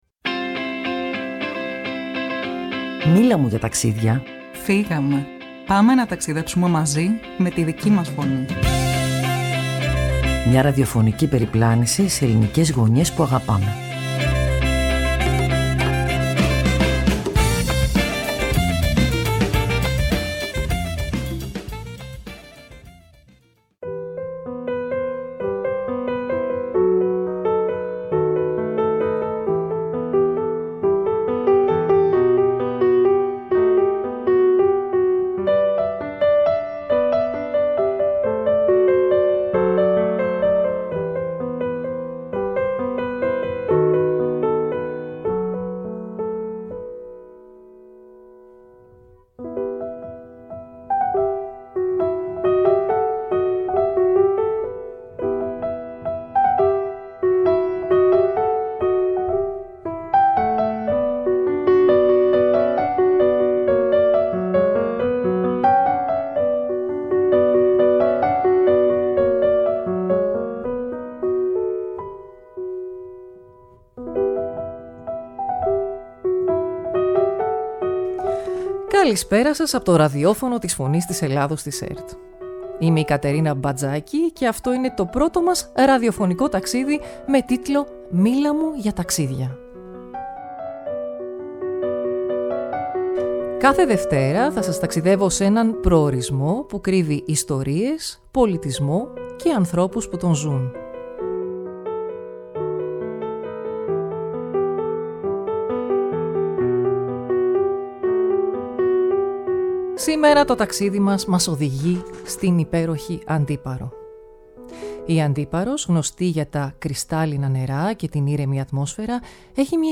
• ο Αρτέμης Τριαντάφυλλος, αντιδήμαρχος Αντιπάρου